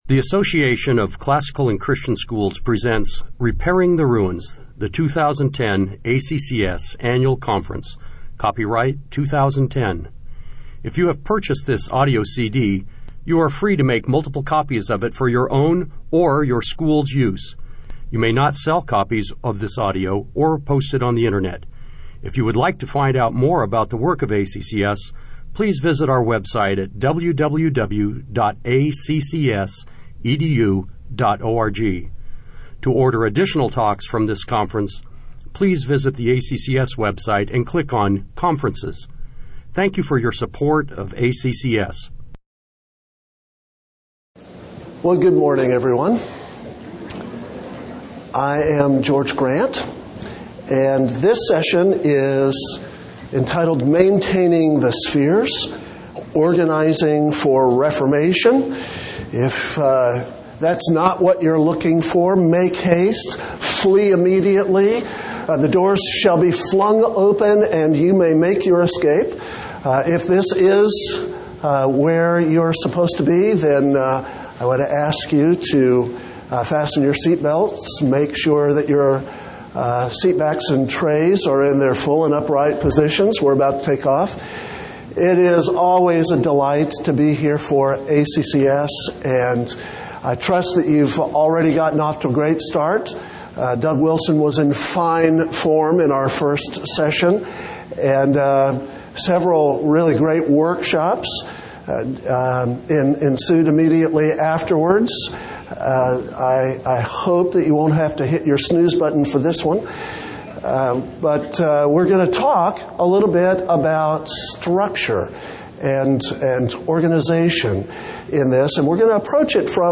2010 Plenary Talk | 1:02:35 | All Grade Levels, Leadership & Strategic